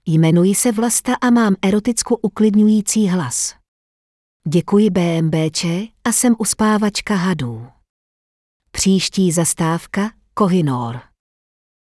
A taky se k tomu hlasu dá dostat a může vám cokolit namluvit.
Takže plechová huba, což u mne nemění názor na hlas.